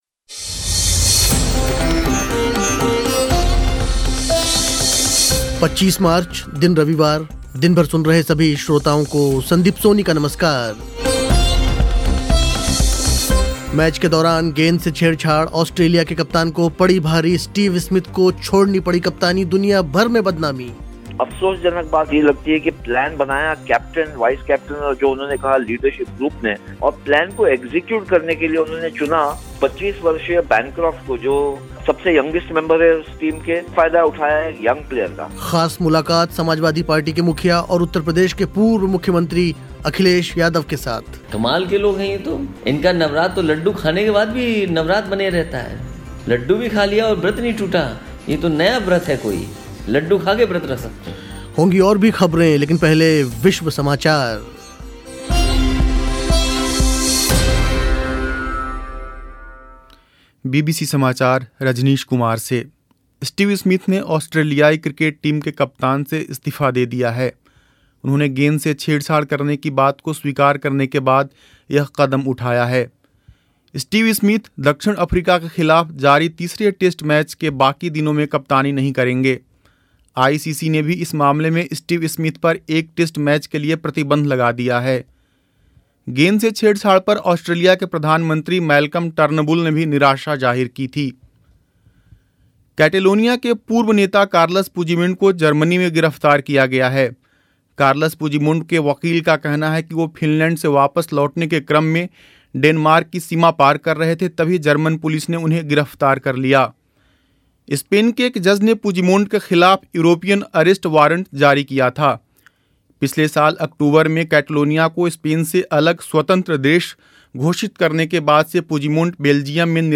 ख़ास मुलाक़ात सपा मुखिया और यूपी के पूर्व मुख्यमंत्री अखिलेश यादव के साथ.